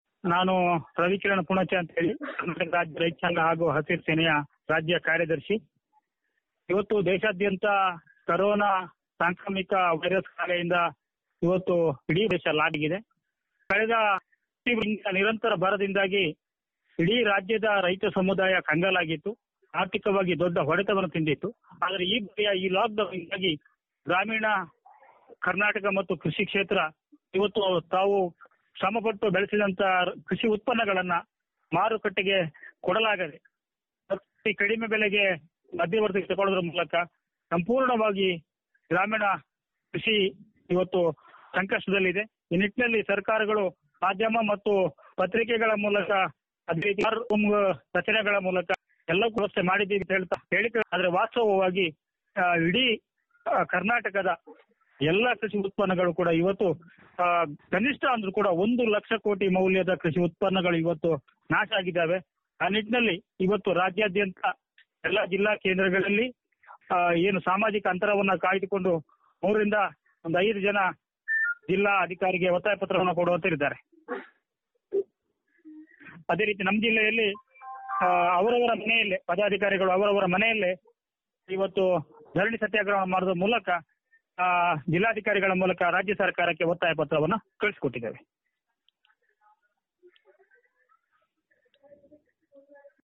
ಅವರ ಹೇಳಿಕೆ ಇಲ್ಲಿದೆ..